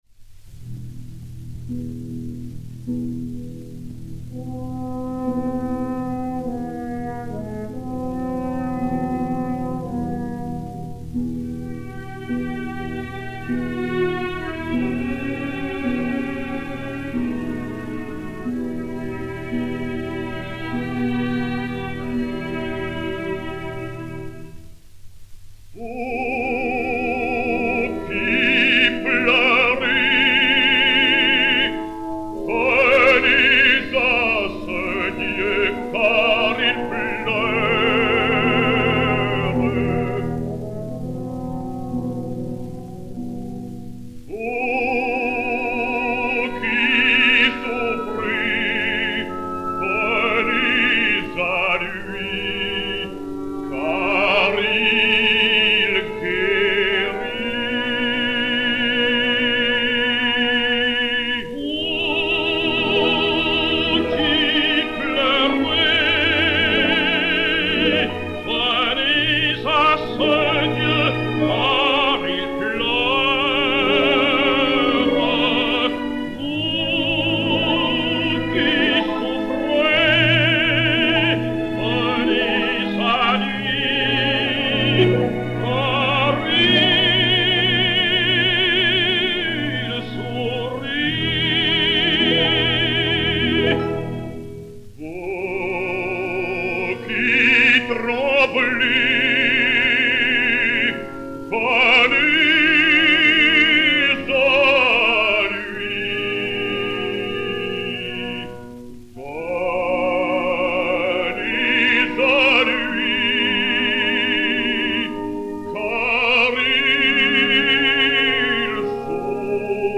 Chant religieux à deux voix